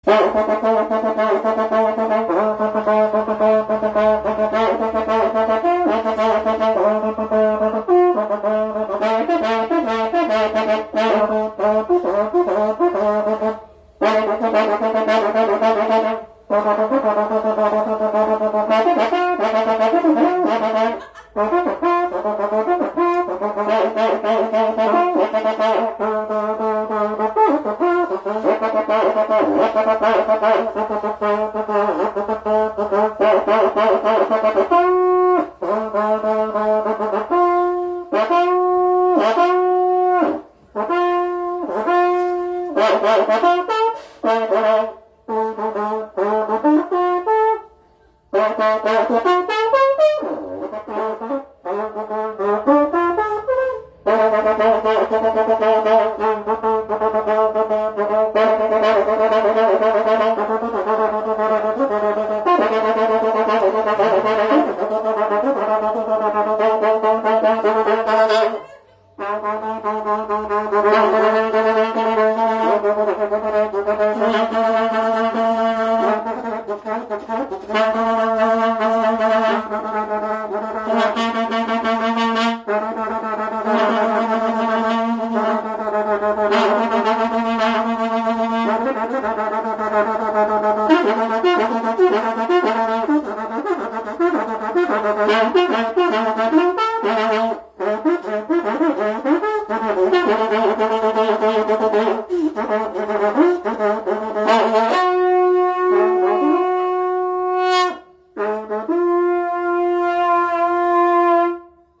Два человека играют на двух карнаях